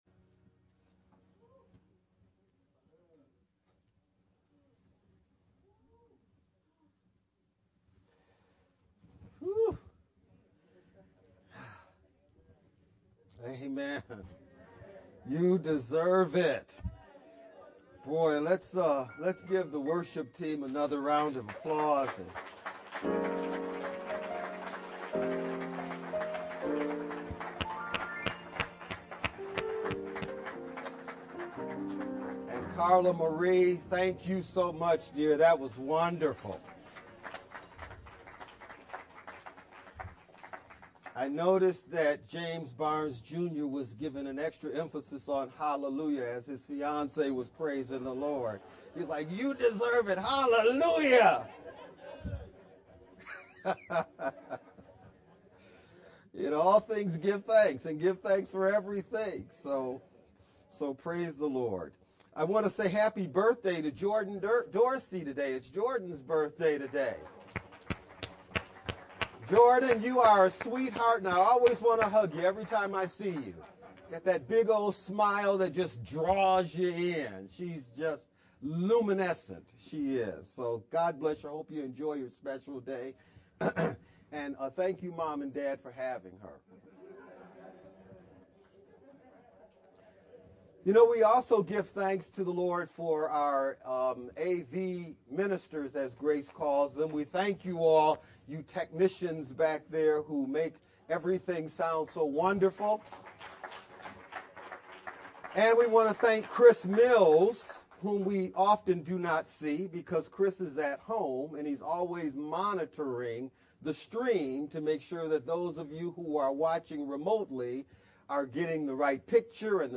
11-27-VBCC-Sermon-edited-sermon-only-CD.mp3